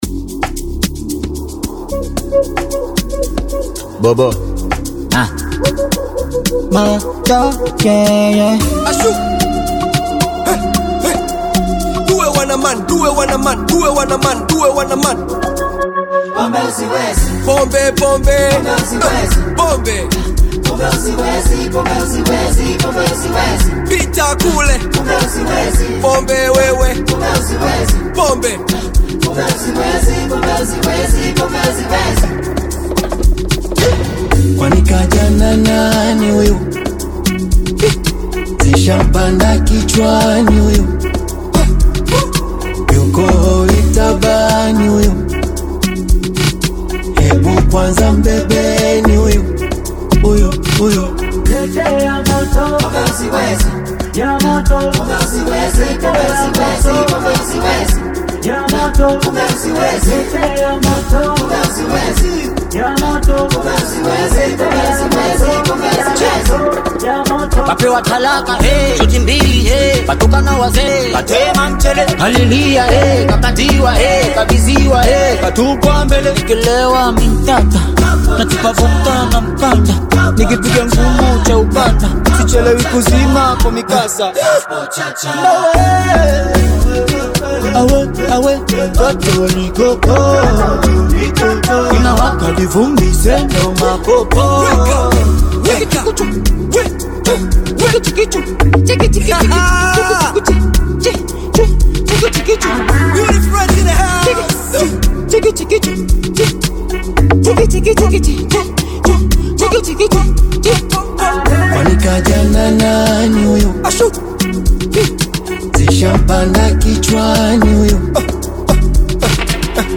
Tanzanian Bongo Flava
rhythmic and danceable experience
African Music